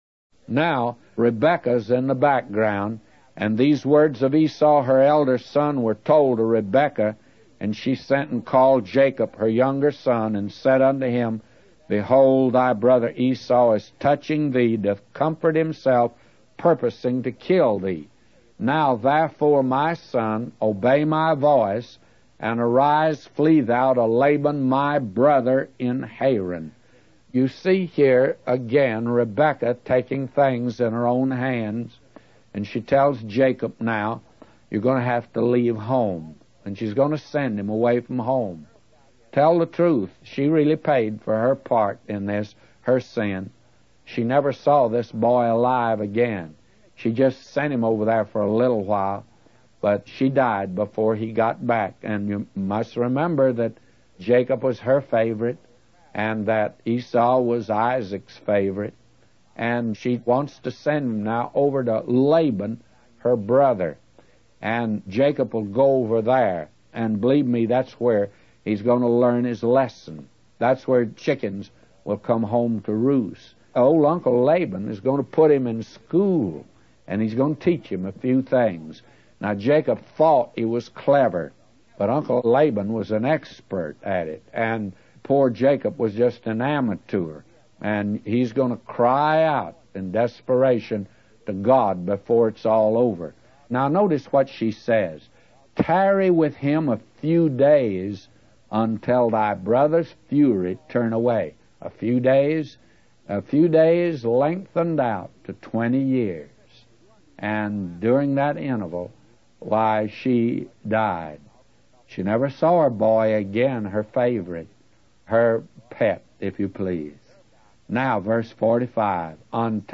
In this sermon, the preacher discusses the story of Jacob and Esau from the Bible.